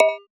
Bounce 3.wav